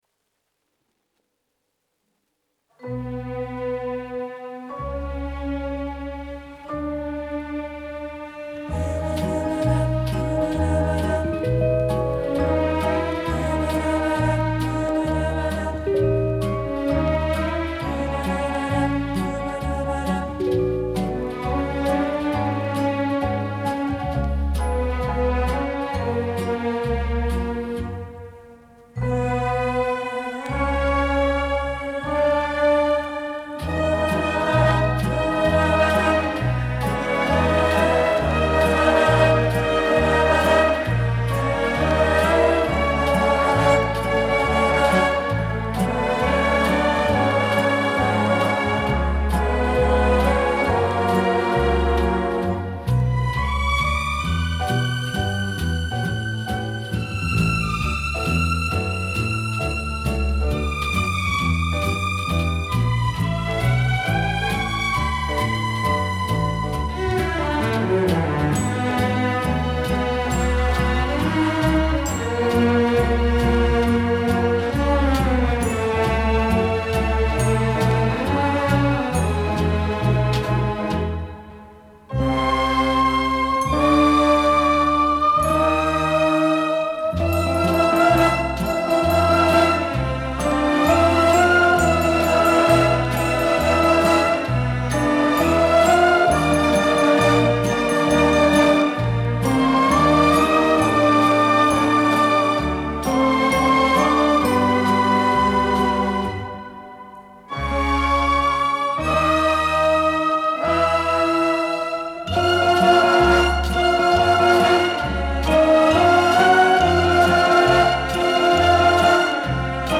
Формат:Vinyl, LP, Stereo
Жанр:Jazz, Pop, Classical, Stage & Screen
Стиль:Easy Listening, Theme